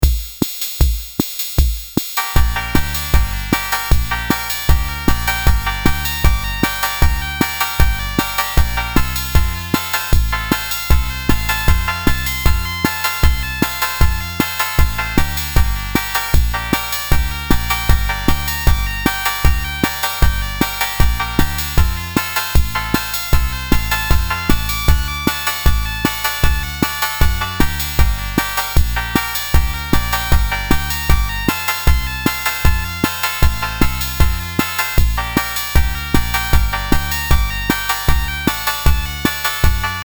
Баян электронный "Орион"
Пожалуй один из самых недооцененных электронных инструментов эпохи СССР.
Электробаян-Орион.mp3